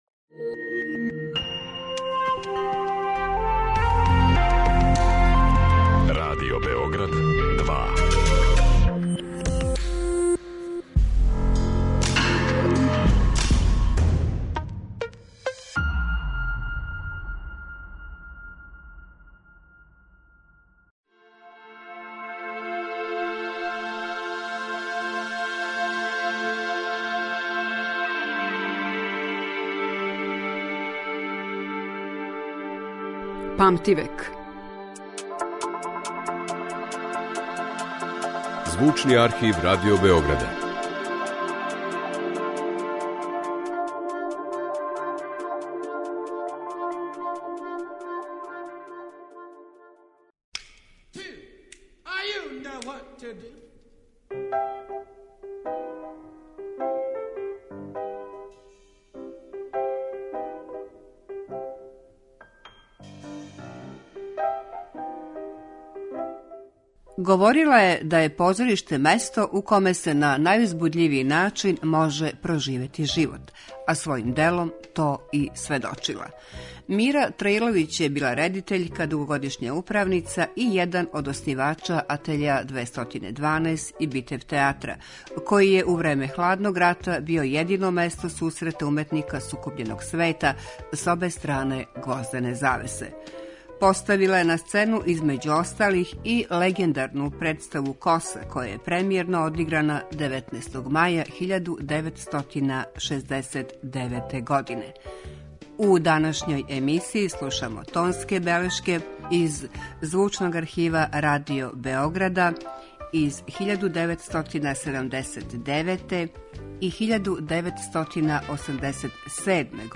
У данашњој емисији слушамо тонске белешке из Звучног архива Радио Београда у којима Мира Траиловић говори о себи и свом схватању уметности и позоришта.